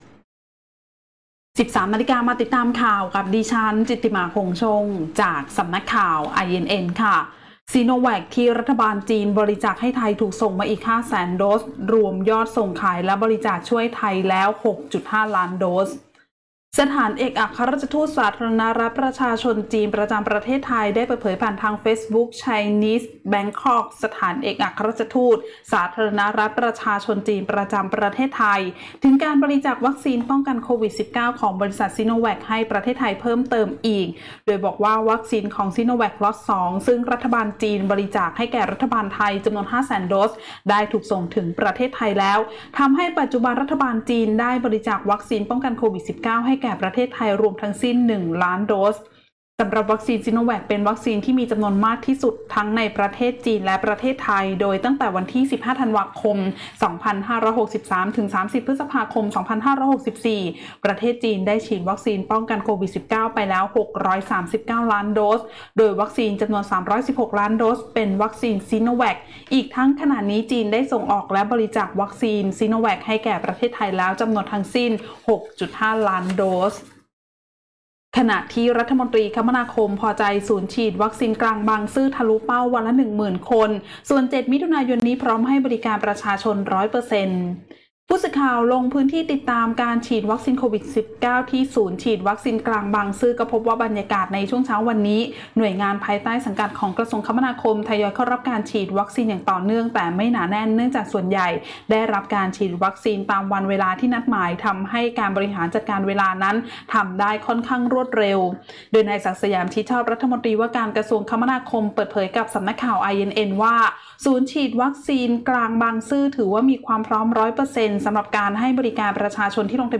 คลิปข่าวต้นชั่วโมง
ข่าวต้นชั่วโมง 13.00 น.